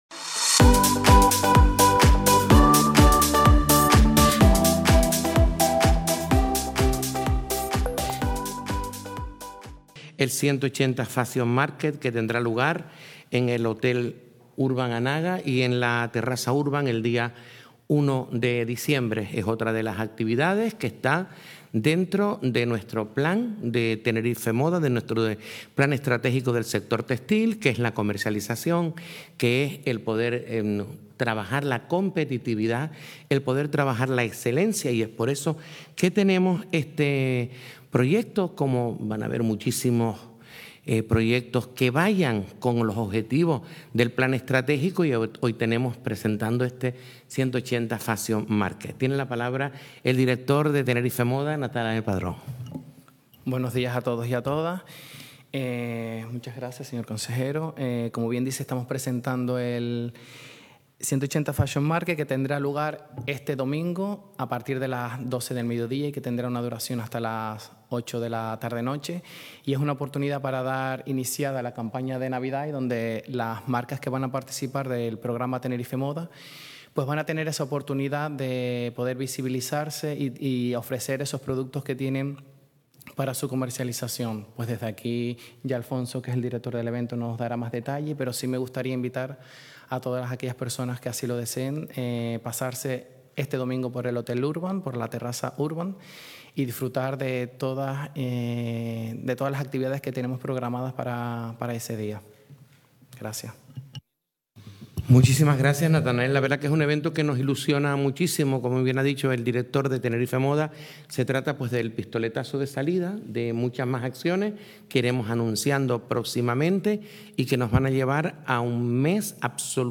El Cabildo de Tenerife ha presentado hoy, la ‘180 Fashion Market’, un evento pensado para inspirar, conectar y dar un impulso a los proyectos en el apasionante mundo de la moda. Así lo han informado hoy (martes) en rueda de prensa por el...